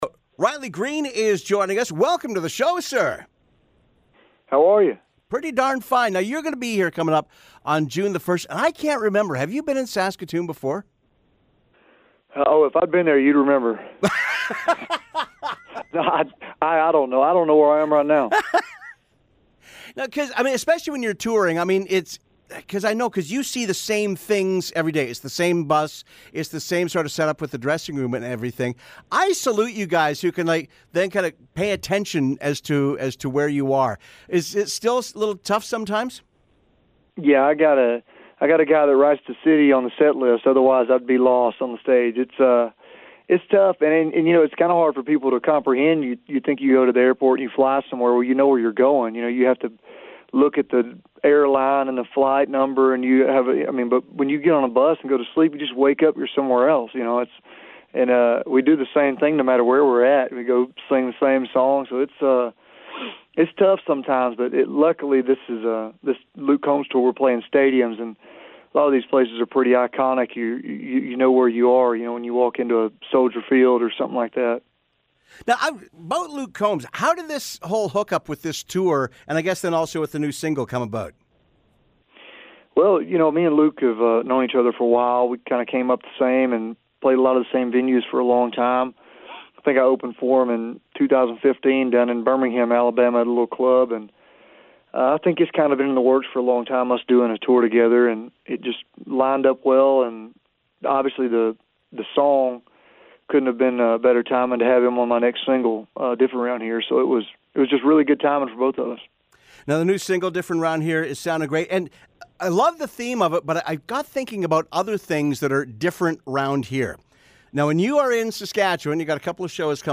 Interview: Riley Green